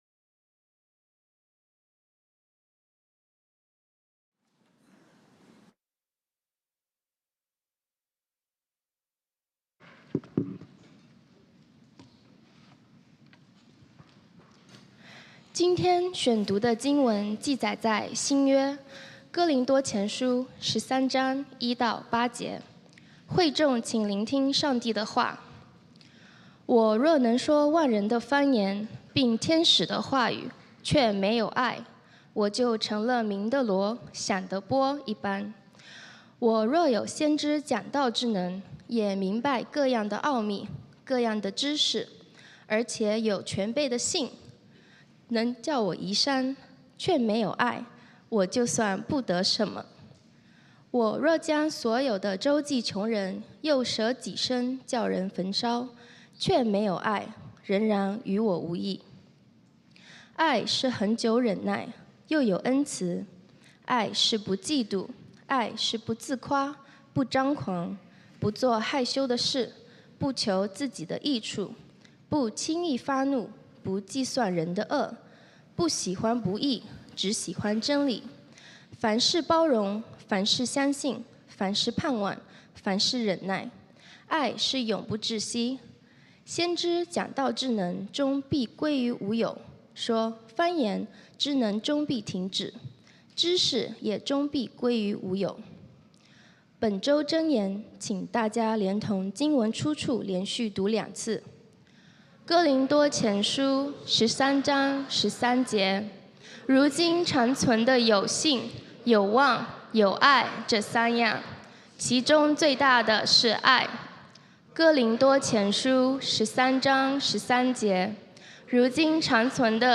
講道經文：哥林多前書 1 Corinthians 13:1-8